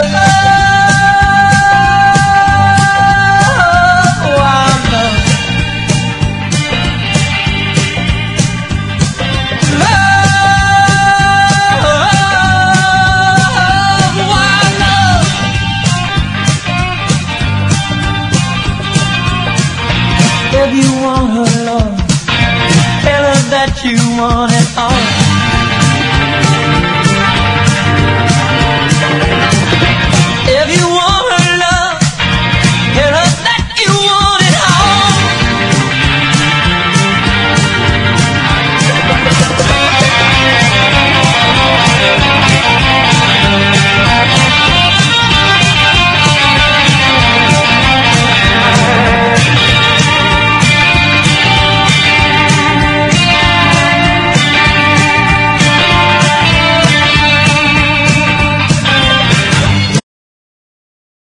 ROCK / 70'S (US) UK
狂ったアレンジが凄すぎるヒット曲カヴァー集！
ノスタルジックなワルツ調にアレンジされた
ストリングスを効かせてUKソフトロック風になってしまった